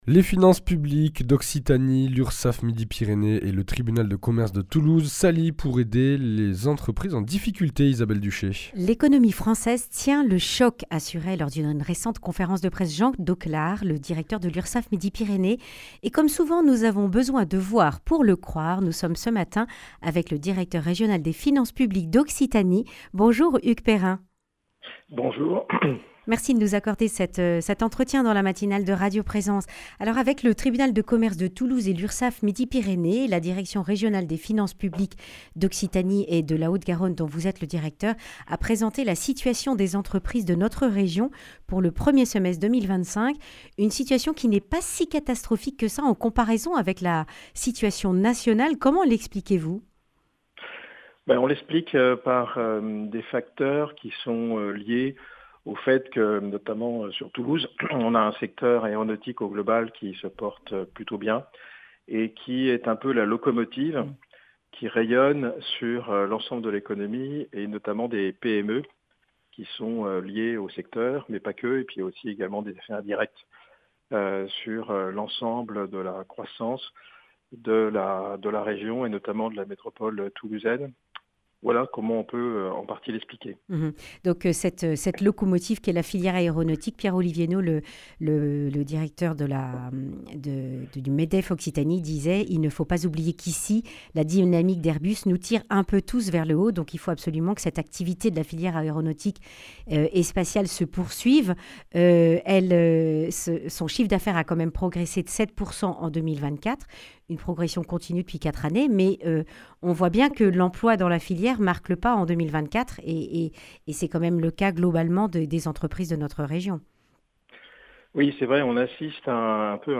Hugues Perrin, le directeur régional des Finances publiques d’Occitanie, présente l’accompagnement dont les entreprises bénéficient et dresse quelques perspectives.
Accueil \ Emissions \ Information \ Régionale \ Le grand entretien \ Entreprises en difficulté, les Finances Publiques d’Occitanie vous soutiennent !